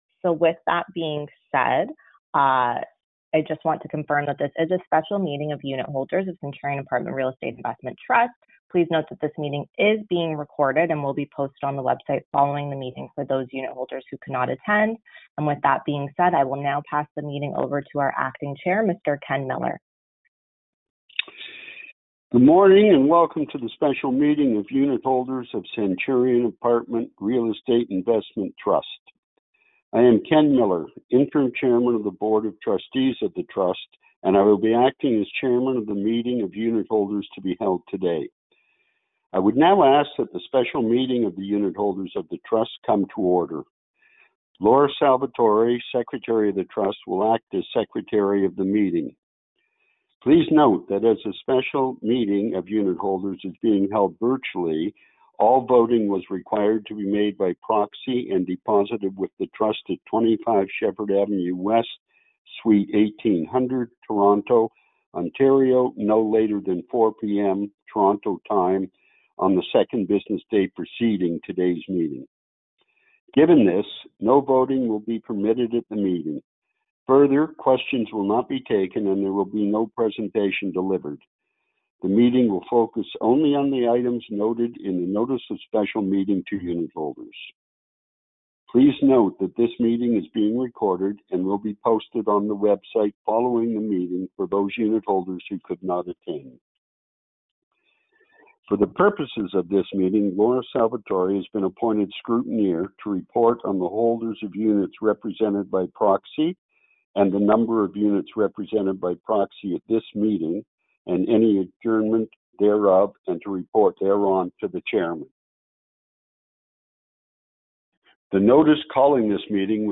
The Special Meeting of Unitholders of Centurion Apartment Real Estate Investment Trust (“REIT”) was held via conference call on November 6, 2025.